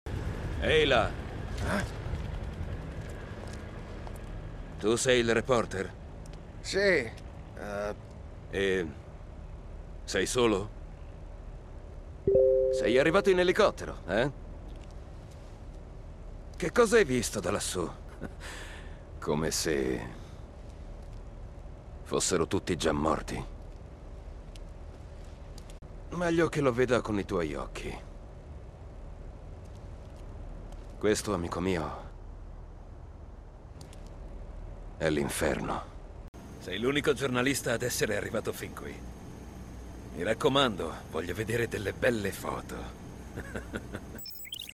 nel videogame "Dead Rising Deluxe Remaster", in cui doppia Carlito Keyes.